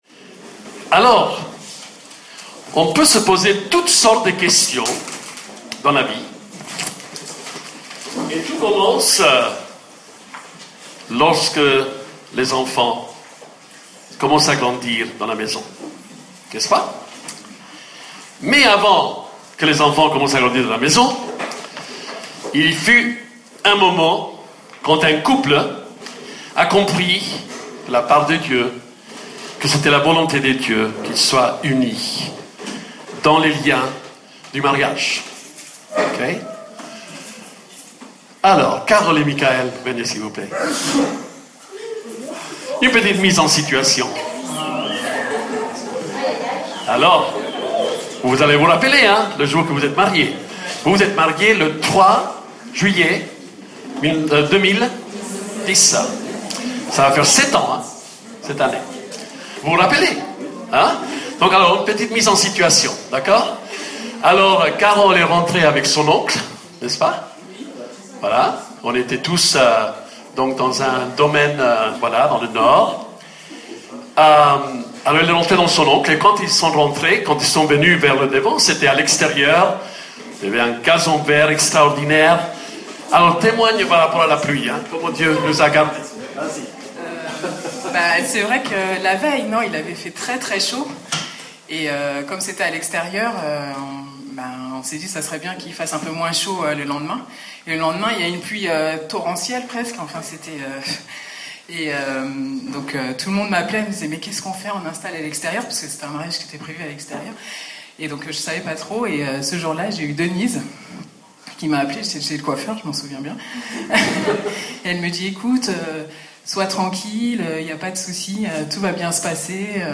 La prédication était excellente avec un enseignement sain, juste, biblique et avec une pointe d’humour.